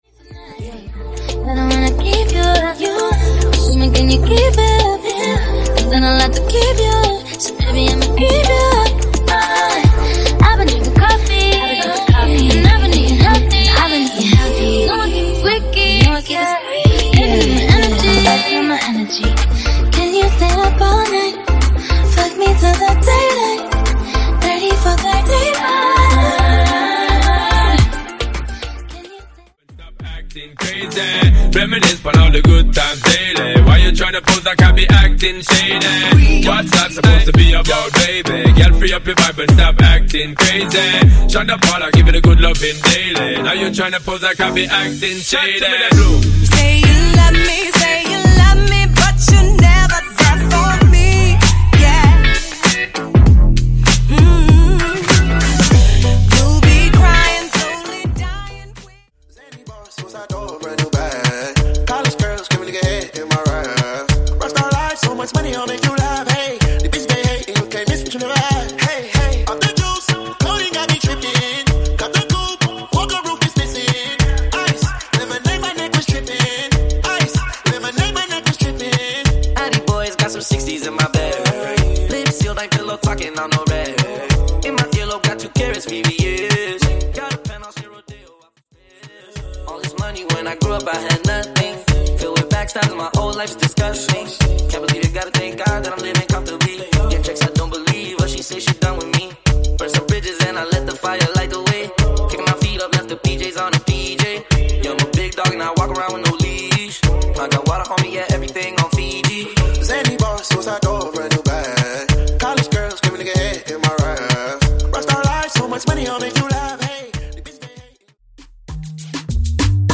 Genres: FUTURE HOUSE , TECH HOUSE